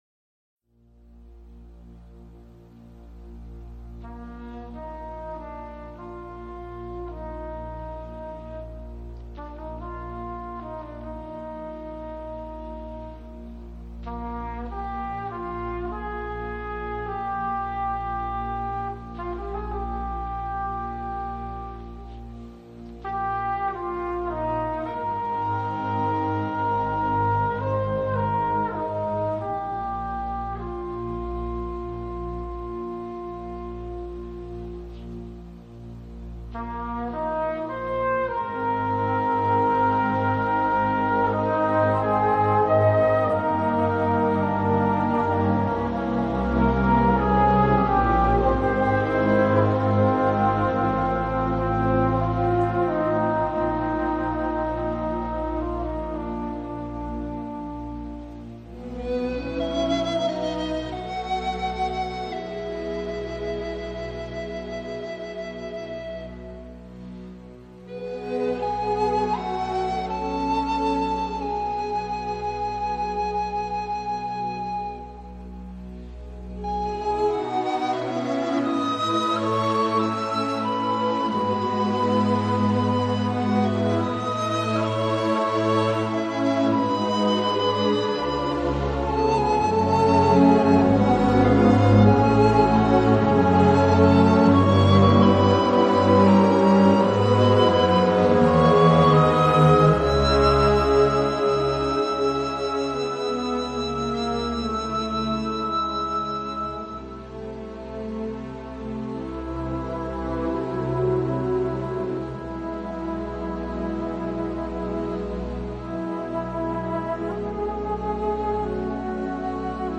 Soundtrack, Orchestral, Neo-Romantic